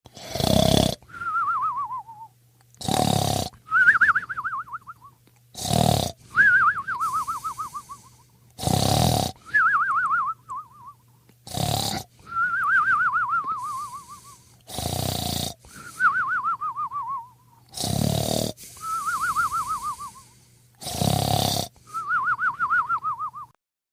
Cartoon Whistle Snoring Sound Effect Free Download
Cartoon Whistle Snoring